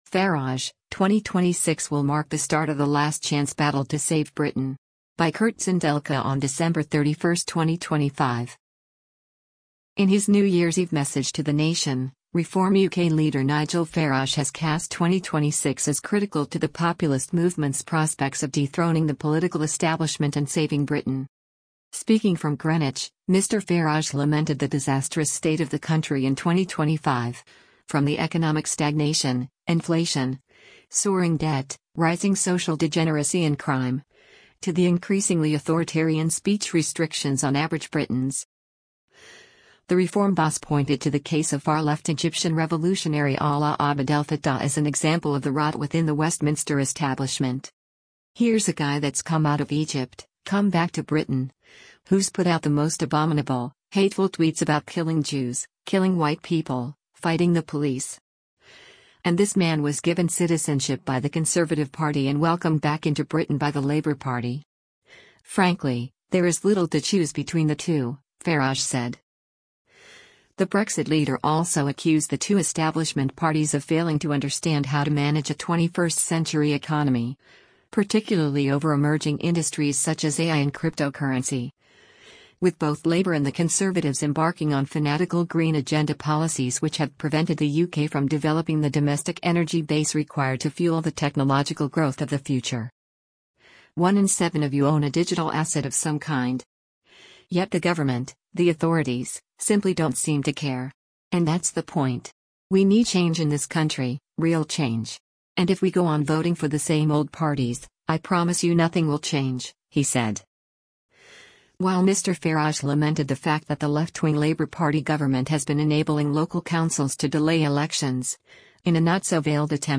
In his New Year’s Eve message to the nation, Reform UK leader Nigel Farage has cast 2026 as critical to the populist movement’s prospects of dethroning the political establishment and saving Britain.
Speaking from Greenwich, Mr Farage lamented the disastrous state of the country in 2025, from the economic stagnation, inflation, soaring debt, rising social degeneracy and crime, to the increasingly authoritarian speech restrictions on average Britons.